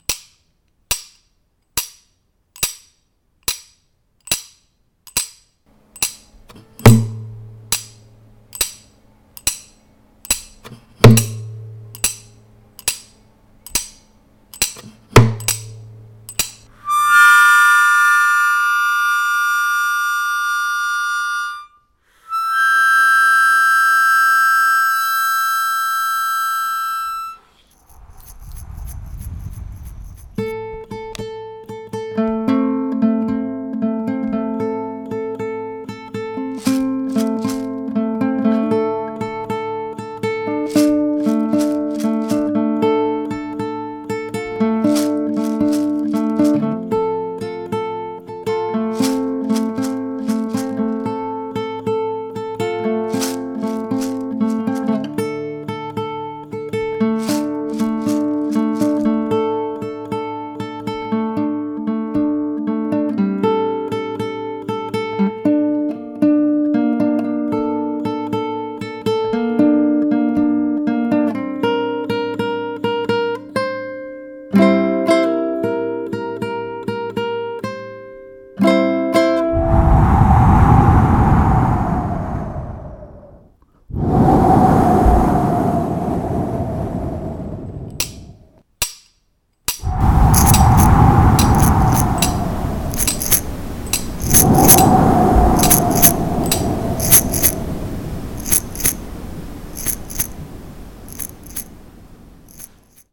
Je compose de manière assez intuitive pour l’instant. C’est plutôt organique.